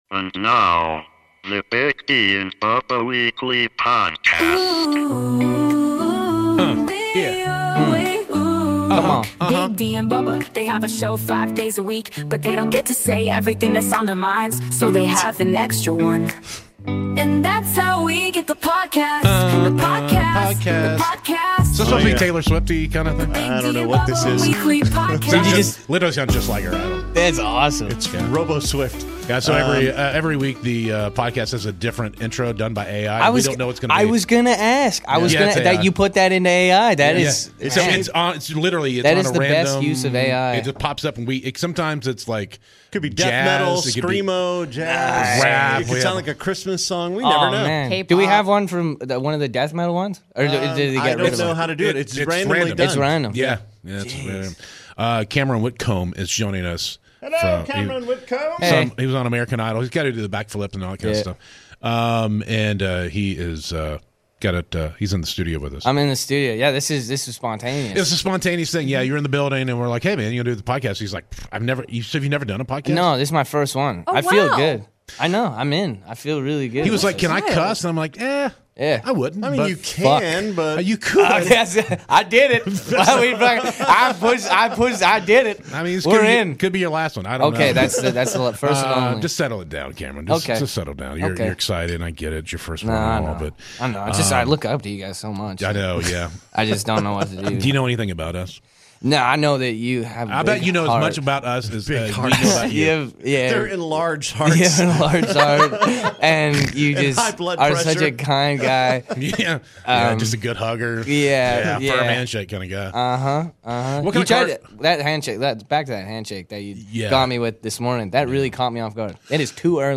Weekly Podcast #538 - It's an enthusiastic and fun conversation with Cameron Whitcomb! Cameron talks about competing on American Idol, his Canadian roots, his music, and much more!